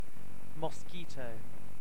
En-uk-mosquito.ogg.mp3